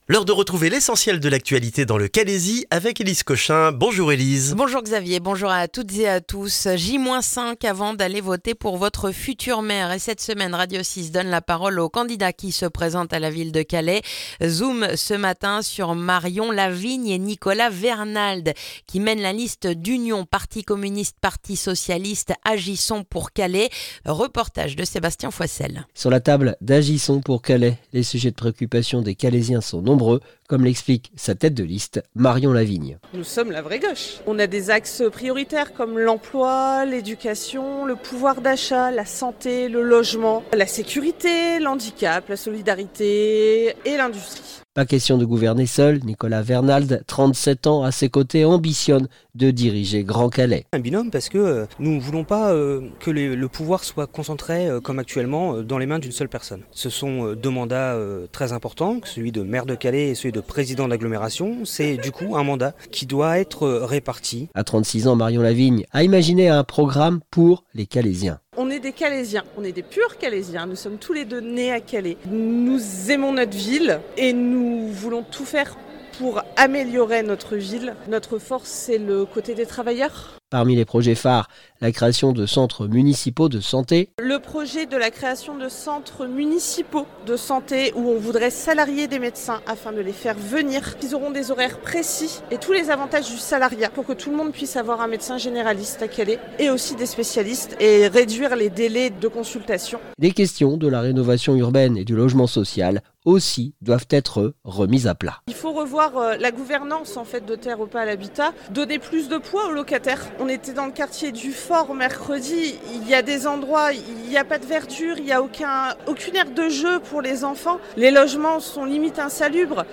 Le journal du mardi 10 mars dans le calaisis